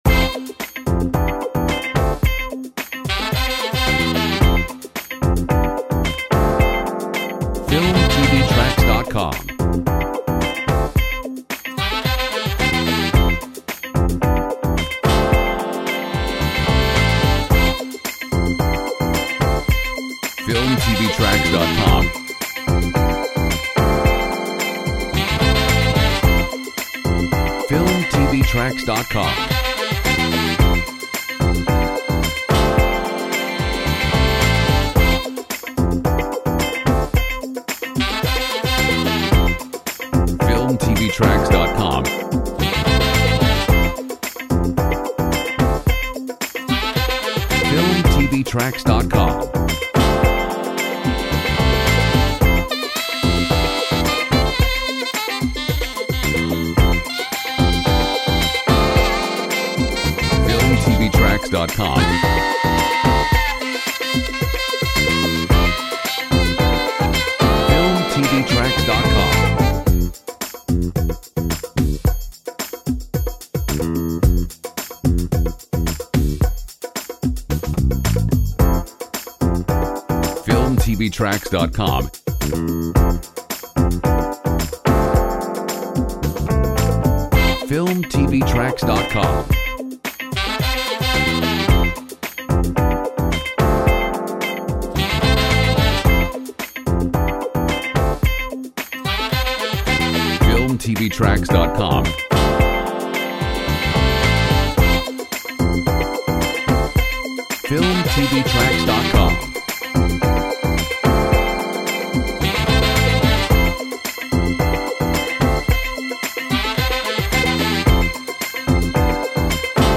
Funky Royalty free music